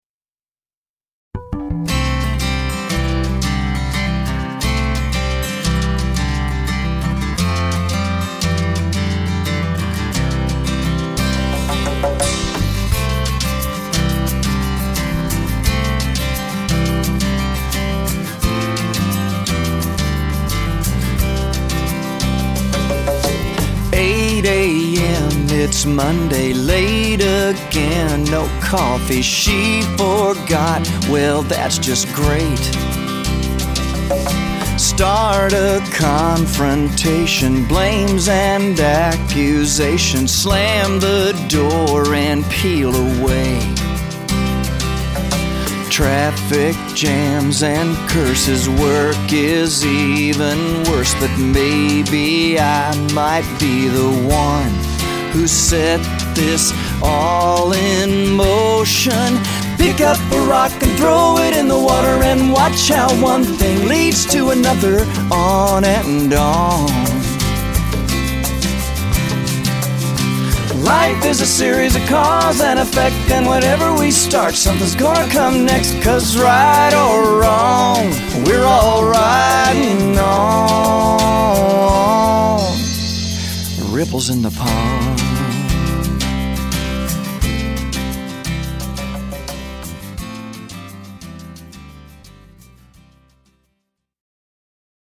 Americana with a touch of country rock